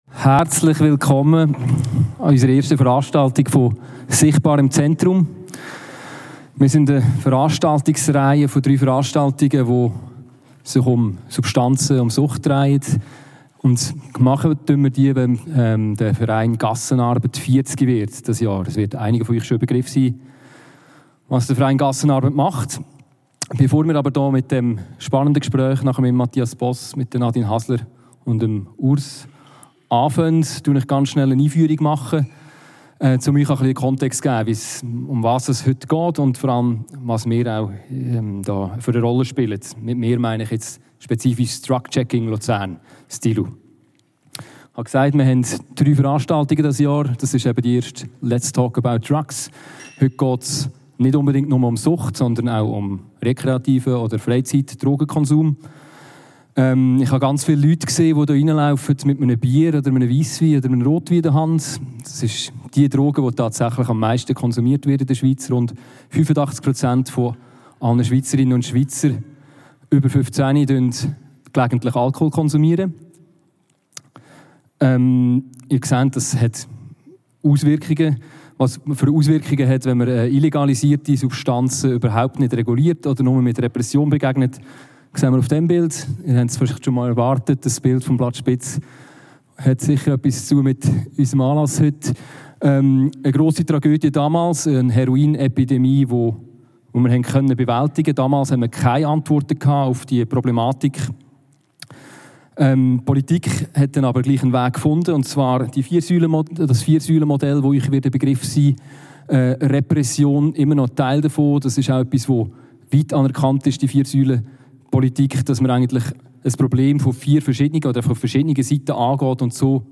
«Let's talk about Drugs» Audio-File zum Podiums-Gespräch im Neubad.